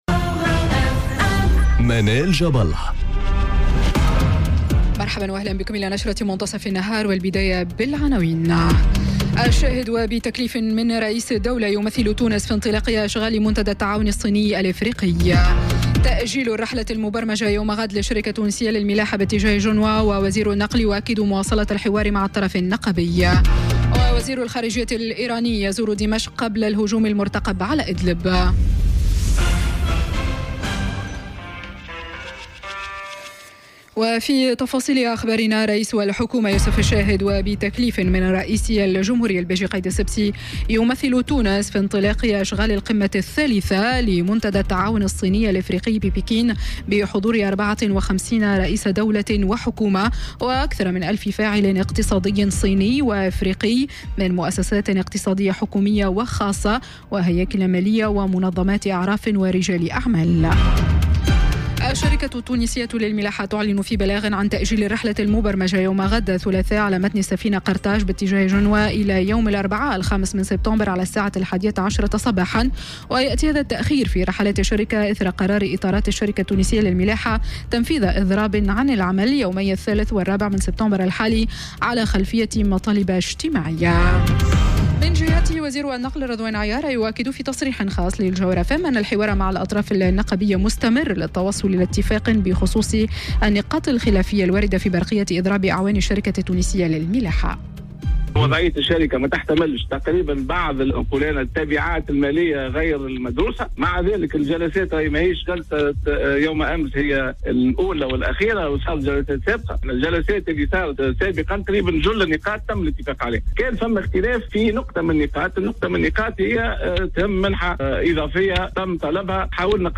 نشرة أخبار منتصف النهار ليوم الثلاثاء 03 سبتمبر2018